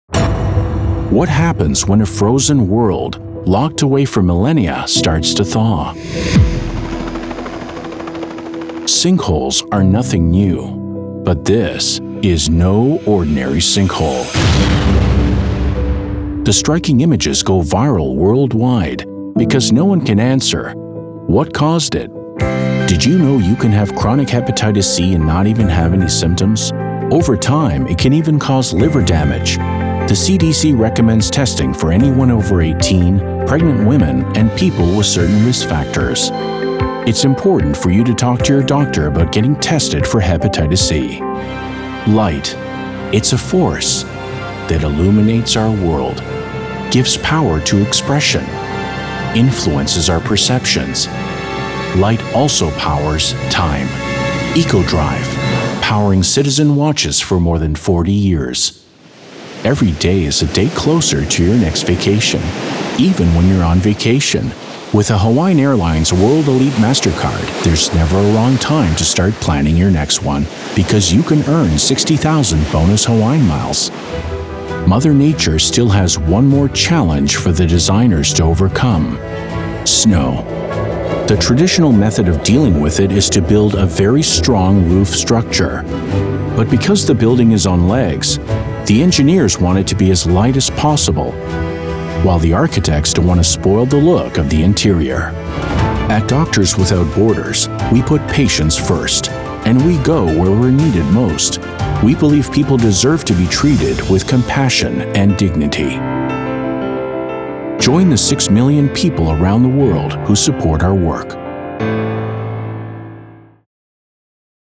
Commercial/Documentary Narration Demo
Middle Aged
My broadcast quality studio includes an acoustically treated iso-booth and industry standard equipment including:
-Sennheiser MKH-416 mic